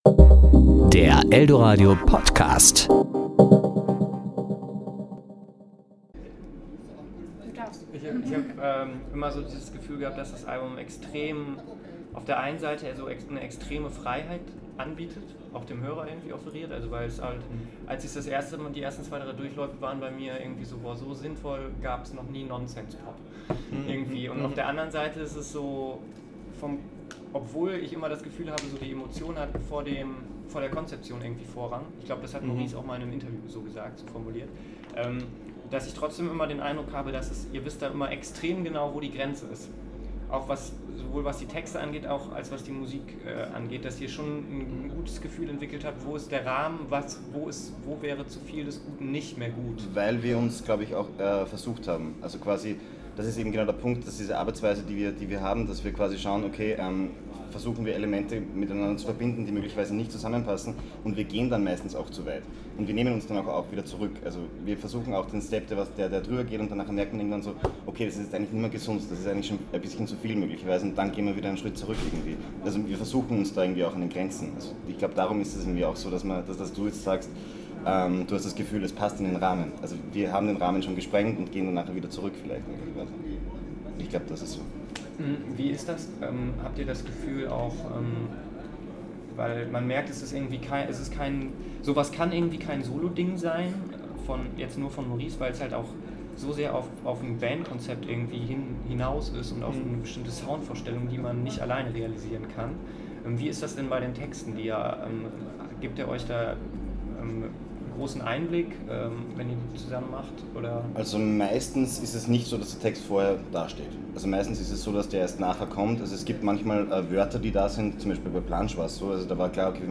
Bilderbuch - Interview auf dem Way Back When 2015
Serie: Interview